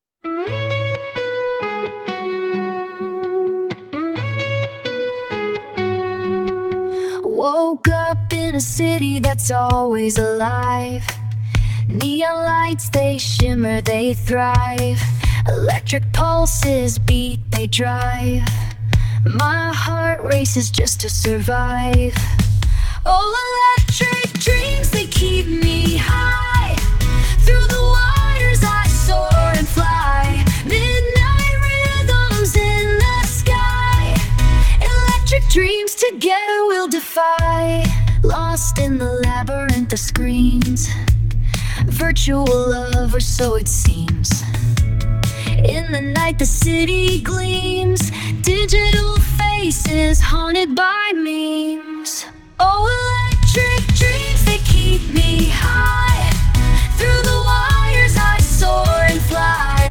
"tags": "synth-pop, electronic, pop, synthesizer, drums, bass, piano, 128 BPM, energetic, uplifting, modern",